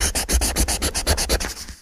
fo_pencil_erasing_01_hpx
Pencil writing and erasing on a tablet of paper. Eraser, Pencil Writing, Pencil